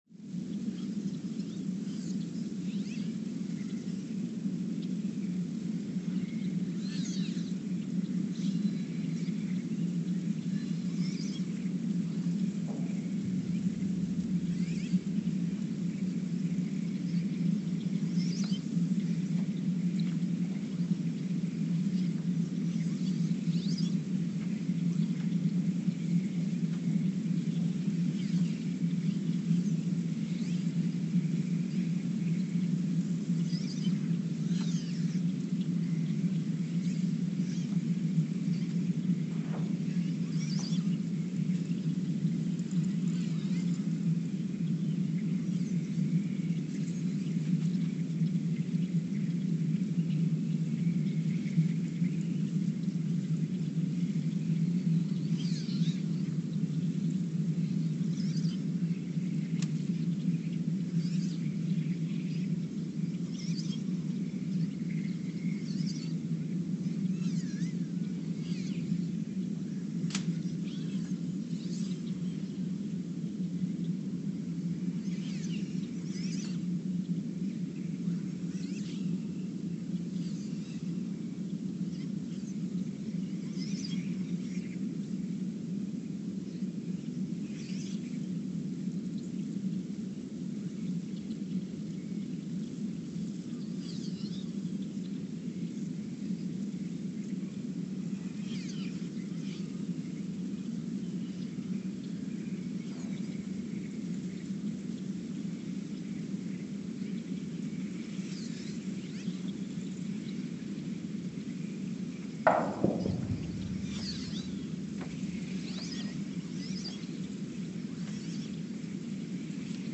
The Earthsound Project: Ulaanbaatar, Mongolia (seismic) archived on November 19, 2023
Station : ULN (network: IRIS/USGS) at Ulaanbaatar, Mongolia
Sensor : STS-1V/VBB
Speedup : ×900 (transposed up about 10 octaves)
Loop duration (audio) : 03:12 (stereo)
Gain correction : 25dB
SoX post-processing : highpass -2 90 highpass -2 90